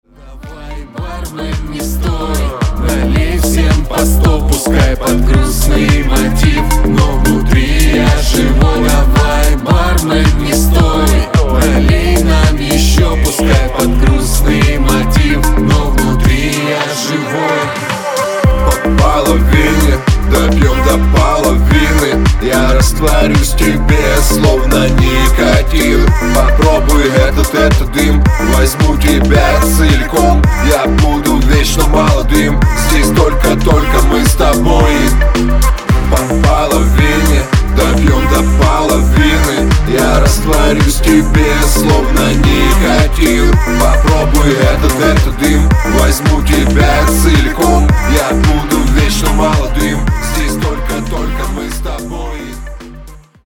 клубняк
house